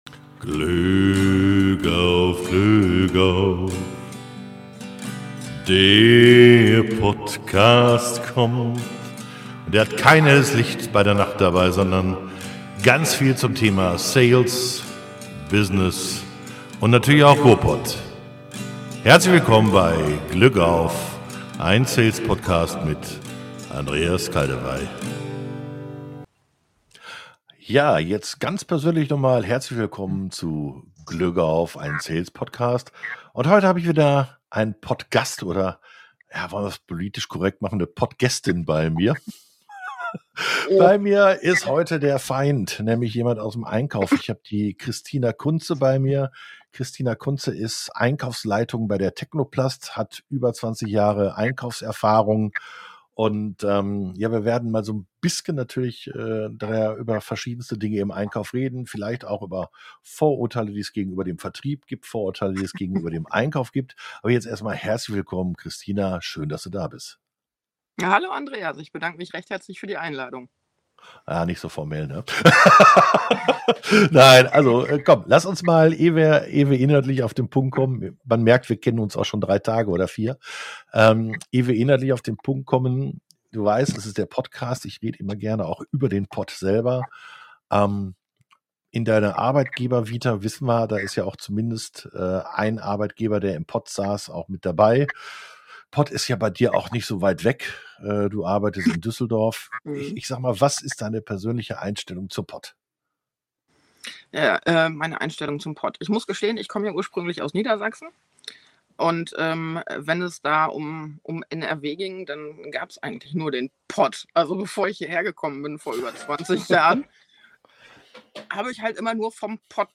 Heute wieder eine Interviewfolge.